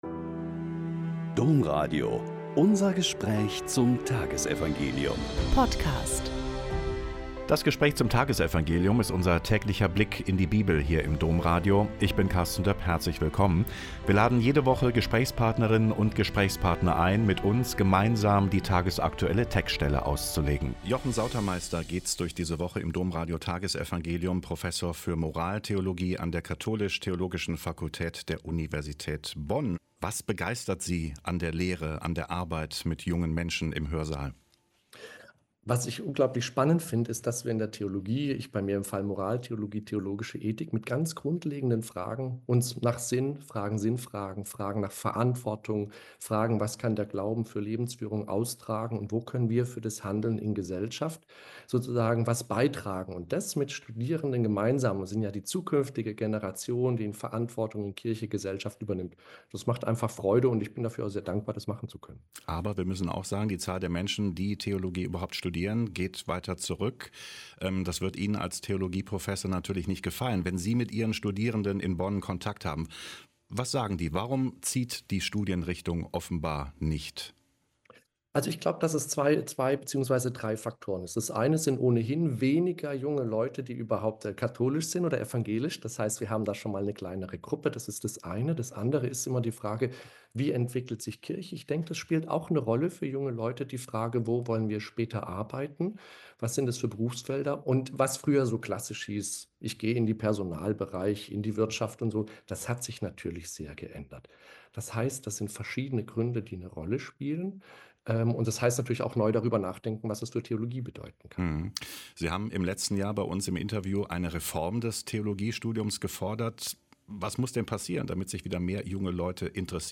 Mt 5,17-19 - Gespräch